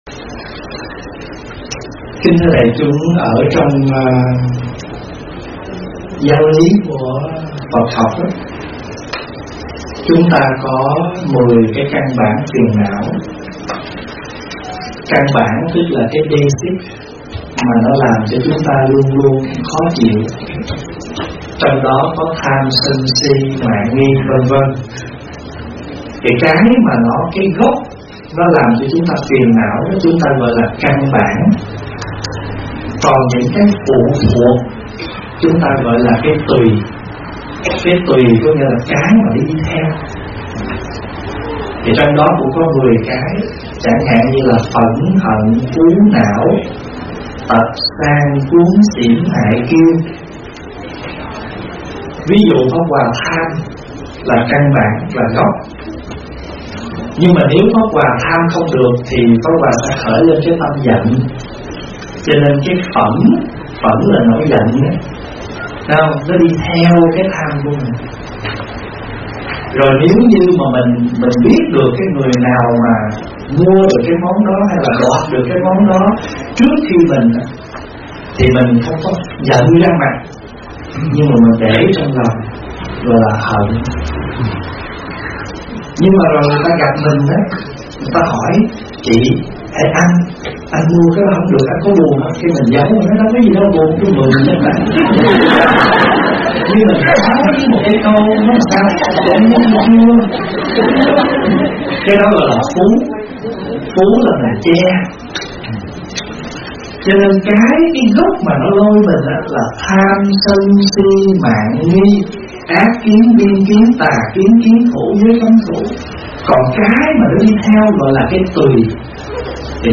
Mp3 thuyết pháp Tâm Ganh Tị Tật Đố Làm Hại Chính Cuộc Đời Của Bạn?